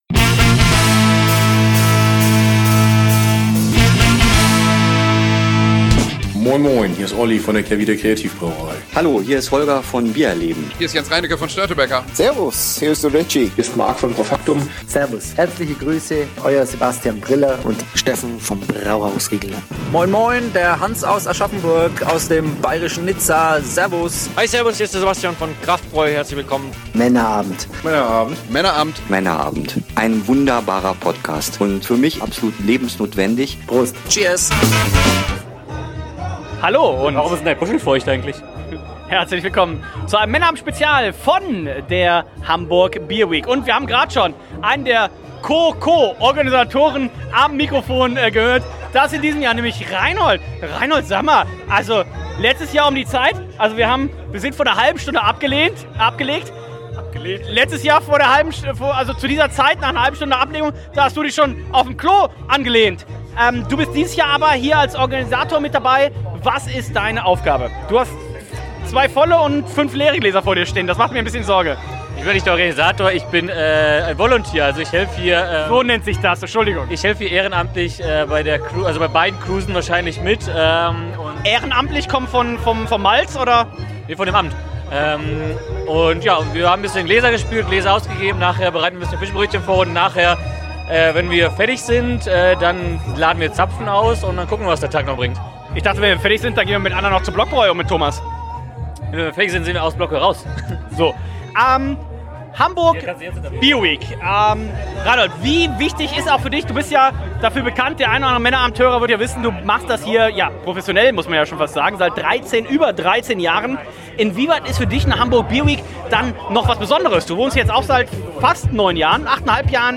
Denn erstmalig gab es in diesem Jahr zusätzlich zur klassischen „ Brewmaster-Cruise “ zur Eröffnung noch eine 2. Schiffsausfahrt die in diesem Jahr unter dem Motto „ Tschechien “ stand. Wir trinken uns durch sämtliche Biere , fangen on Board spannende Stimmen für euch ein und klären hoffentlich die spannende Frage „ Warum ist Dein Puschel feucht eigentlich ?! „.